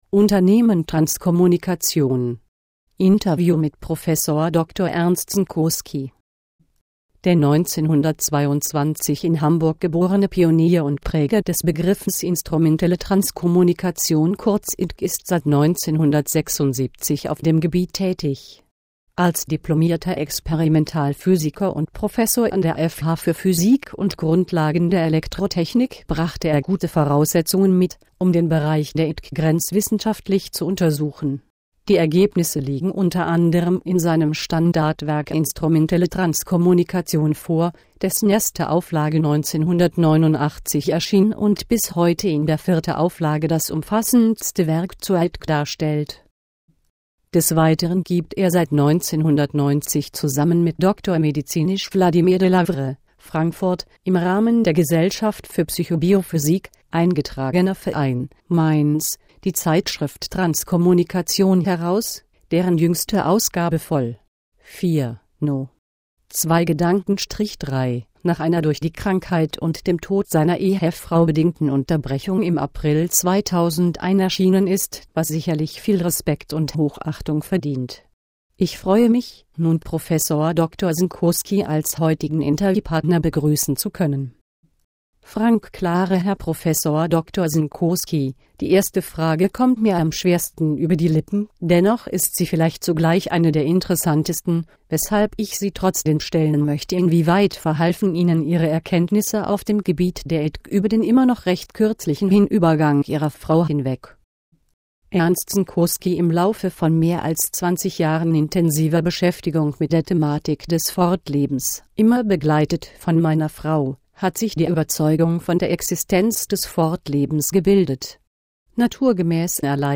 Unternehmen Transkommunikation - Ein Interview